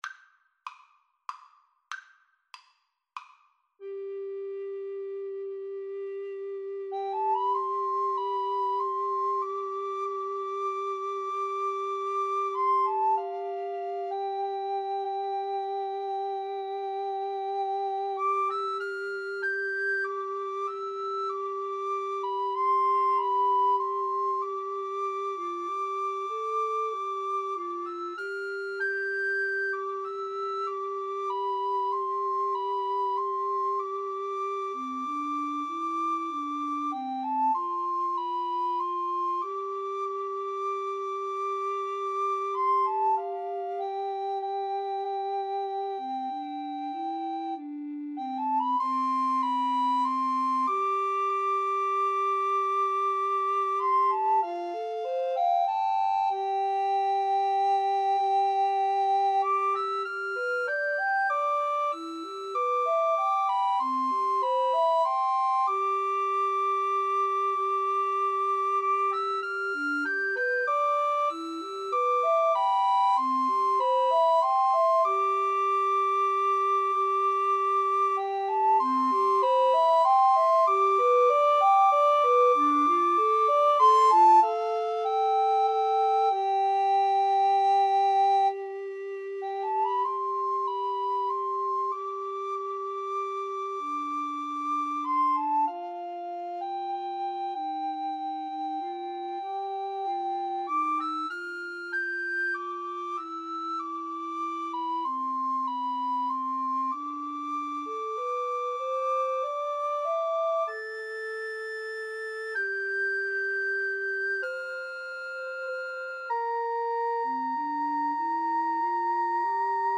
Soprano RecorderAlto RecorderTenor Recorder
3/4 (View more 3/4 Music)
C major (Sounding Pitch) (View more C major Music for Recorder Trio )
Andante = c. 96